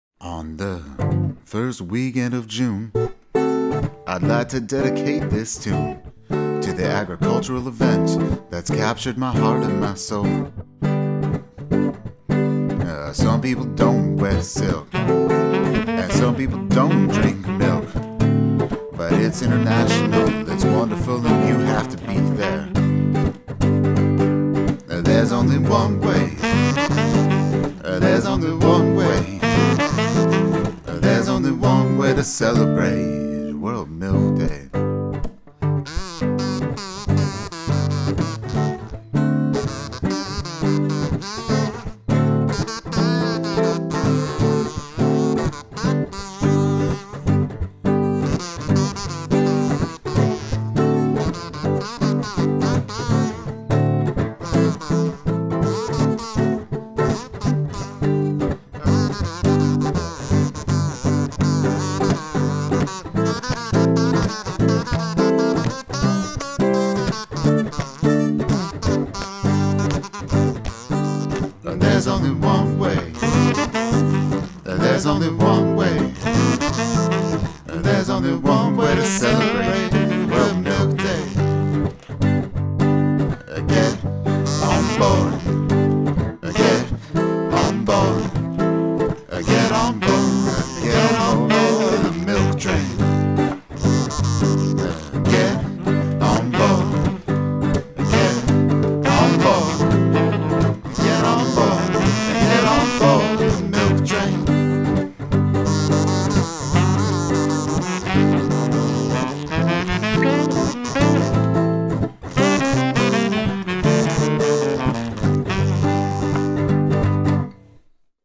Guitar, Vocals, Kazoo, Saxophone, Percussion